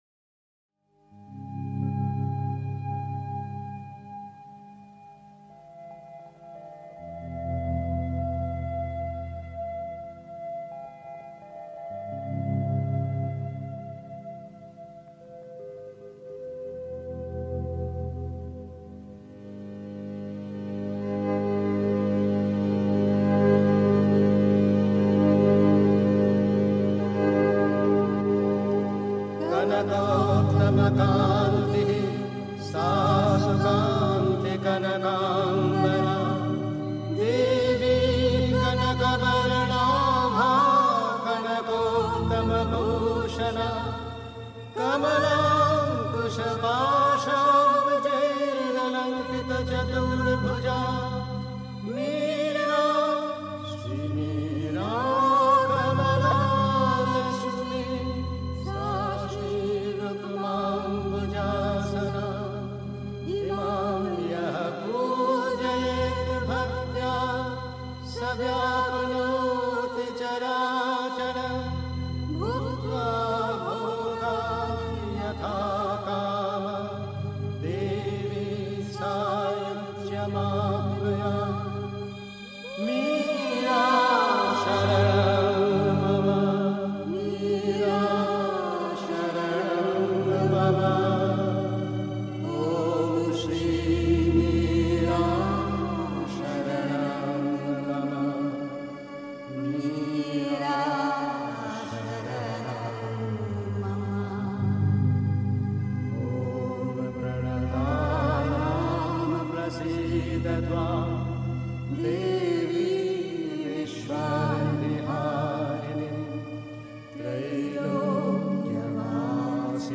Pondicherry. 2. Vier sehr große Ereignisse in der Geschichte (Sri Aurobindo, Thoughts & Aphorisms) 3. Zwölf Minuten Stille.